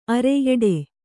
♪ are eḍe